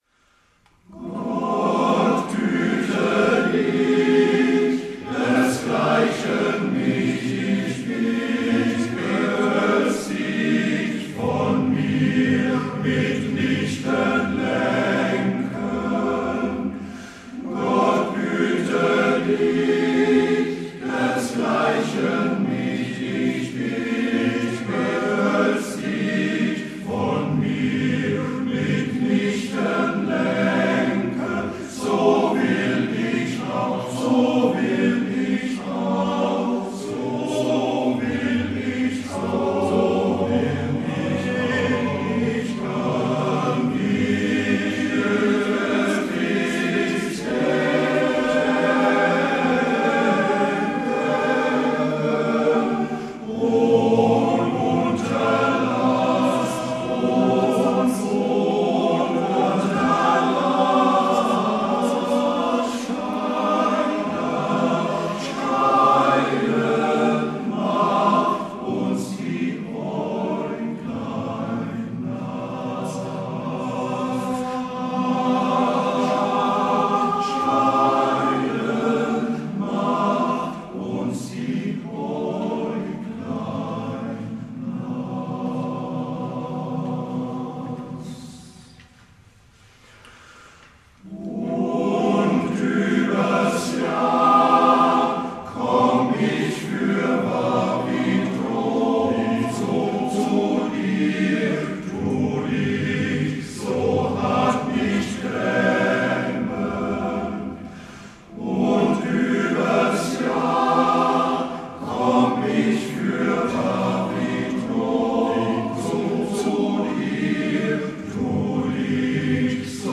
Chormusik
Die folgenden Mitschnitte entstanden auf dem Volksliederwettbewerb 2014 in Bernbach.